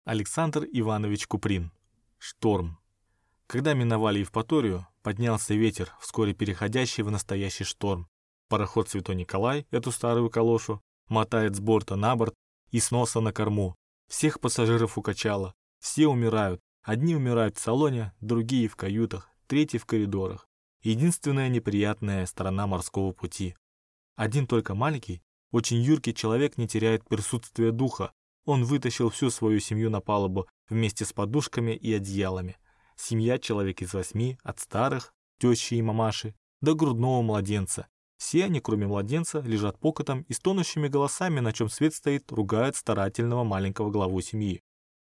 Аудиокнига Шторм | Библиотека аудиокниг